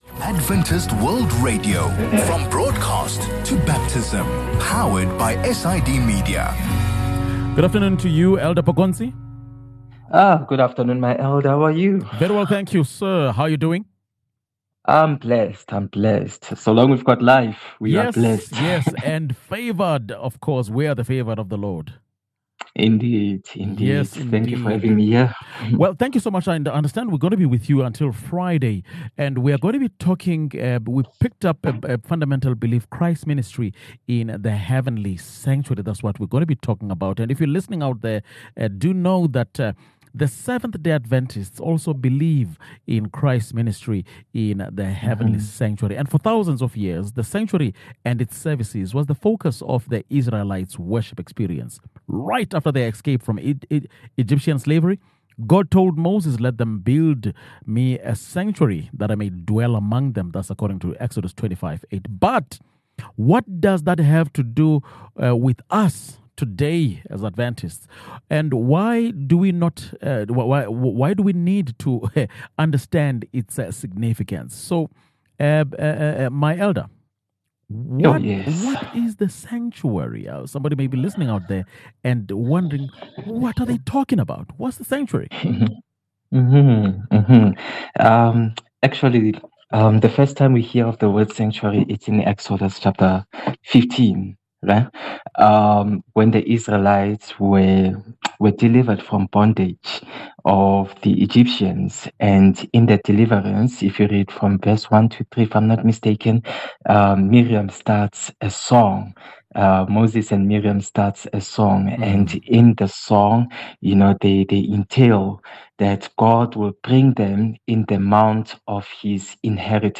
A lesson on the importance and significance of the sanctuary in our present day.